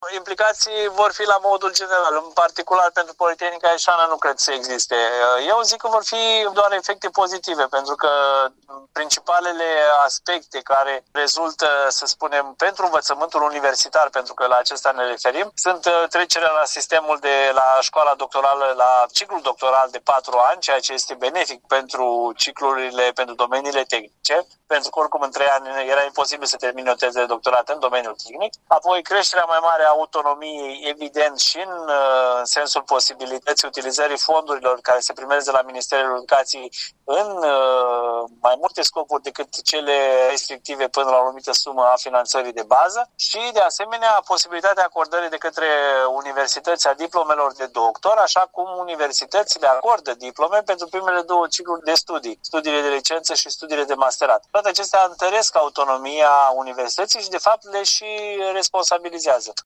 în cadrul dialogurilor și analizelor pe marginea noii legi, dezbateri prilejuite de Zilele Politehnicii.